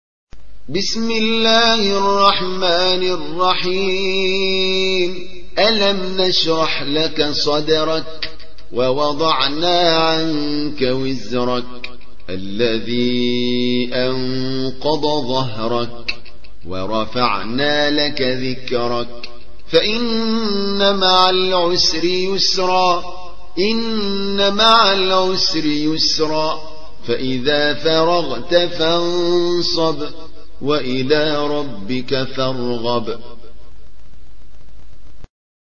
94. سورة الشرح / القارئ